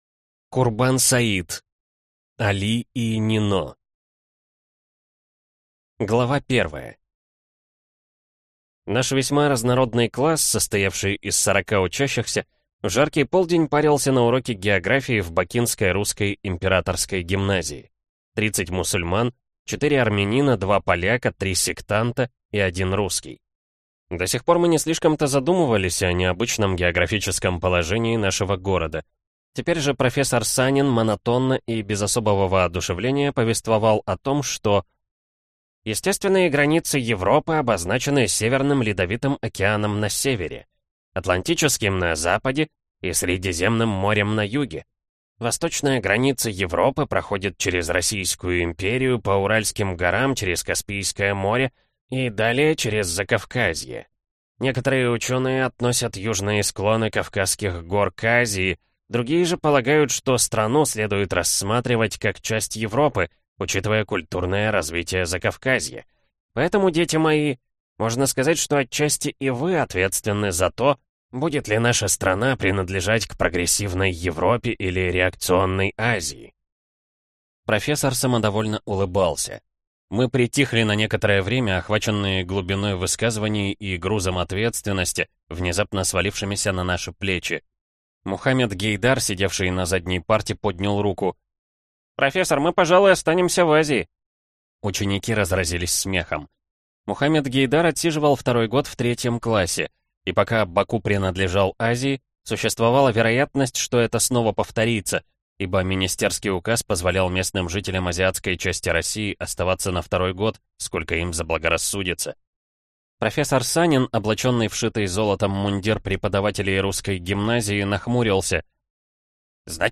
Аудиокнига Али и Нино | Библиотека аудиокниг